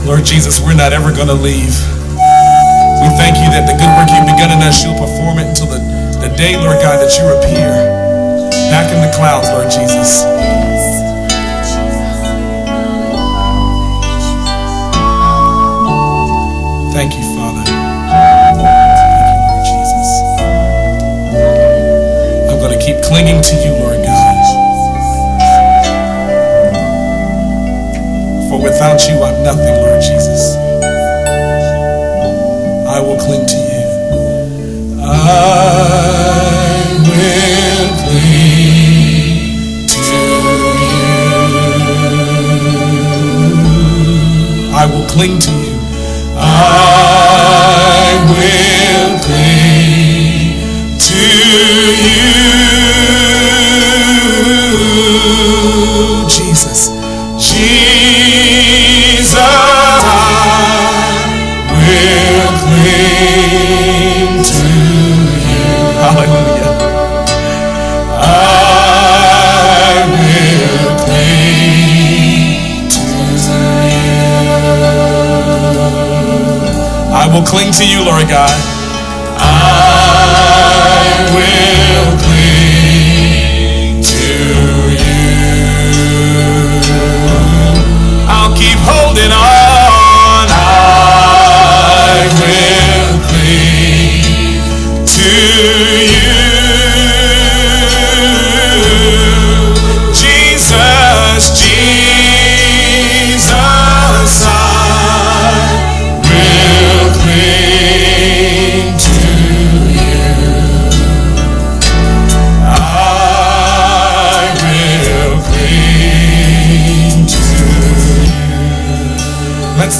VOCALIST
A Classic Gospel Compilation